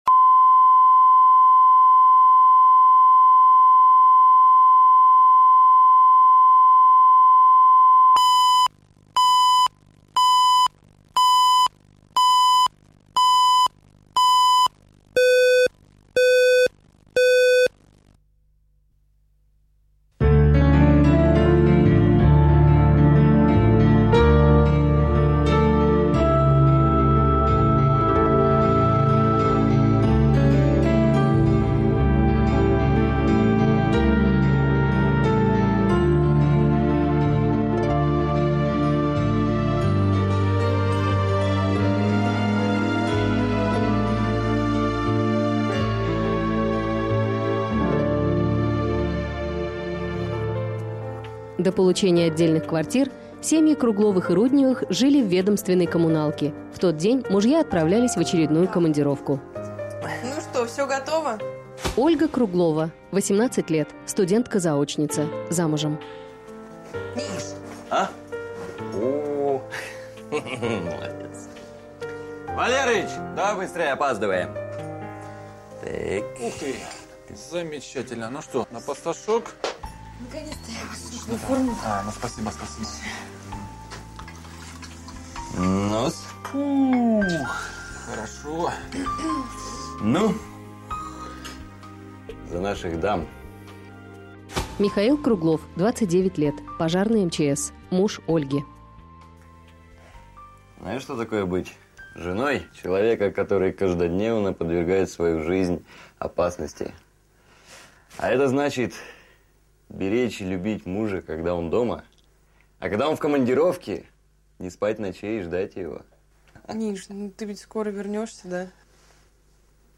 Аудиокнига Чужая ложь | Библиотека аудиокниг